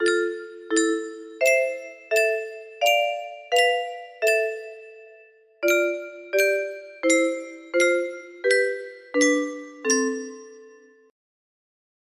music box melody
Full range 60